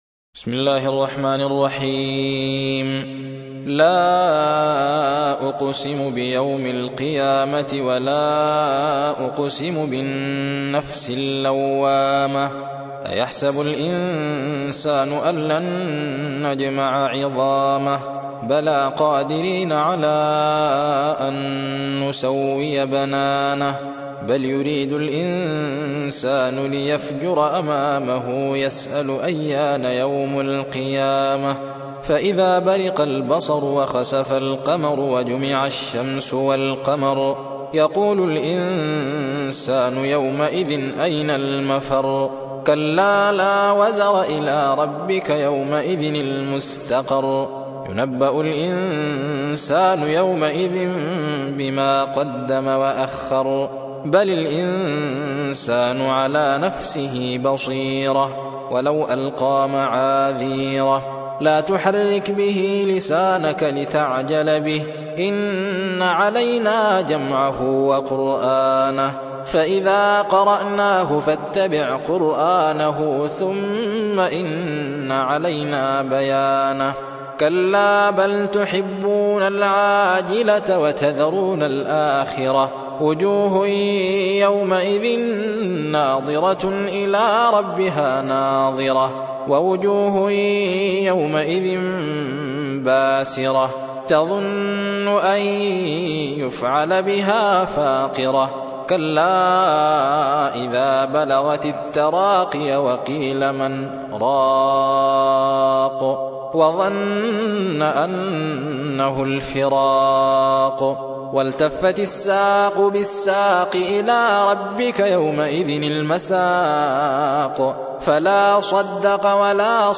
75. سورة القيامة / القارئ